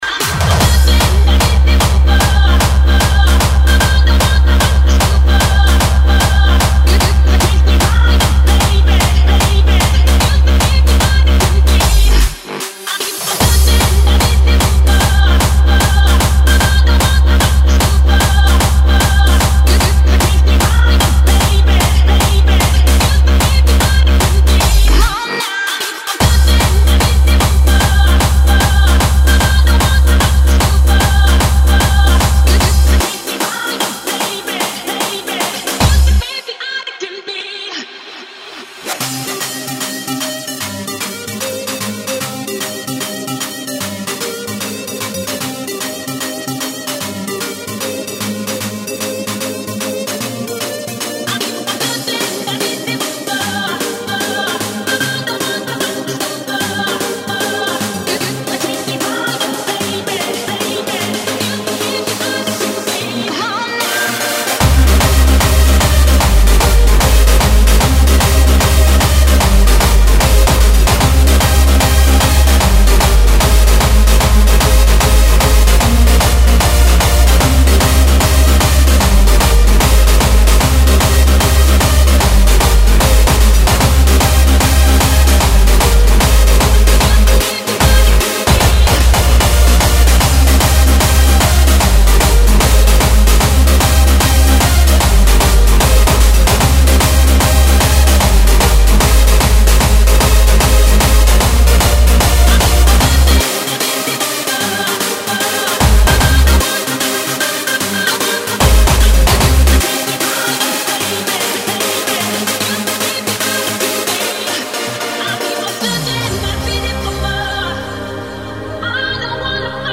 Techno / trance.